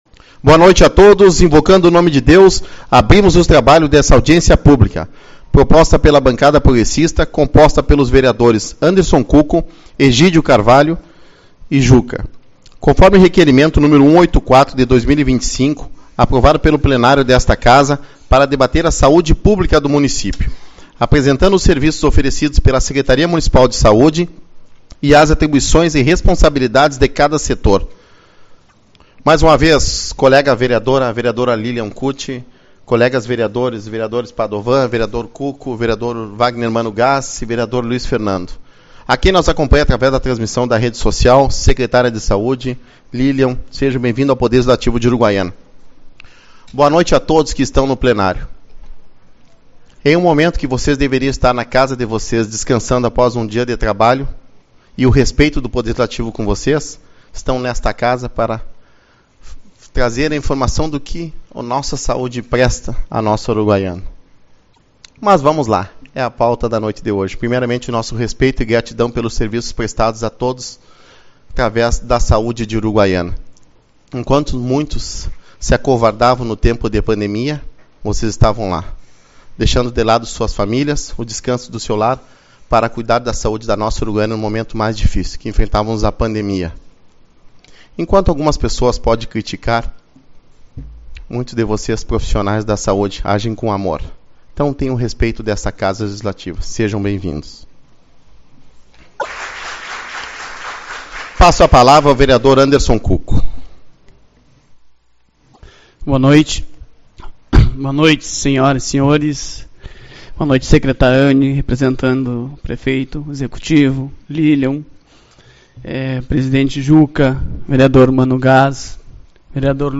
26/03 - Audiência Pública-Saúde Pública